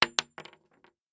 Bullet Shell Sounds
rifle_wood_2.ogg